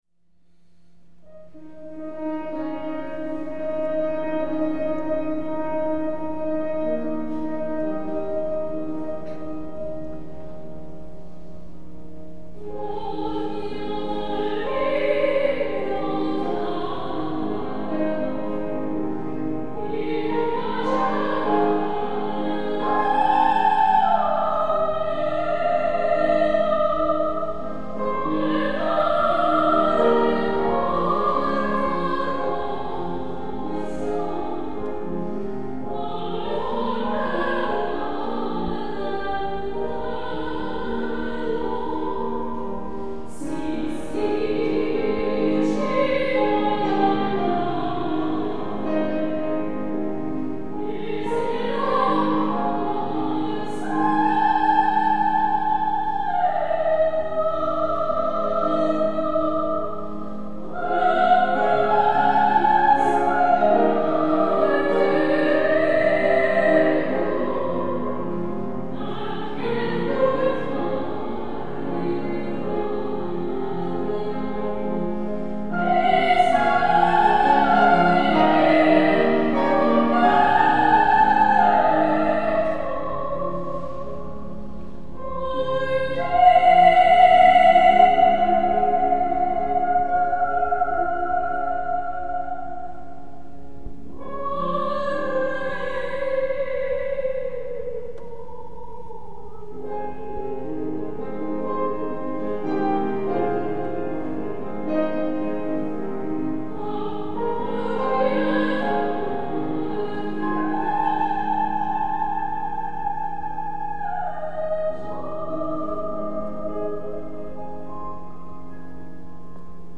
Tracks 3,7 recorded in Southwark Cathedral